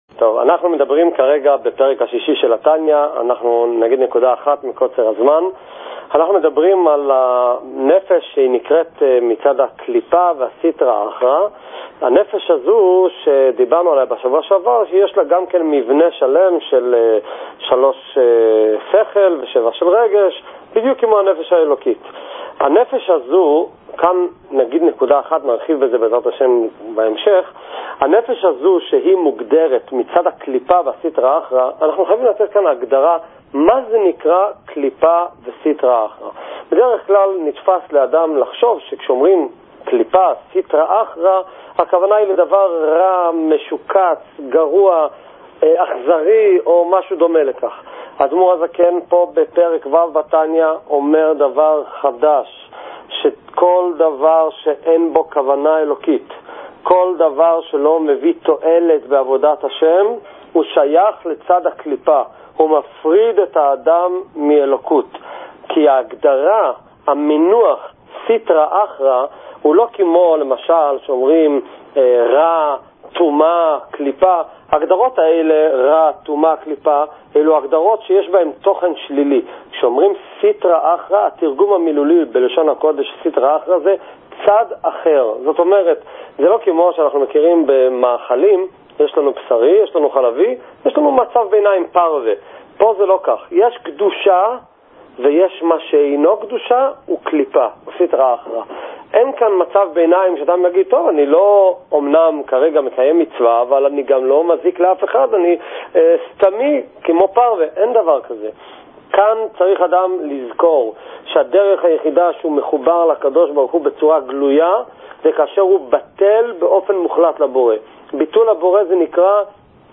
שיעורי תניא | חומש עם רש״י | שיעורי תורה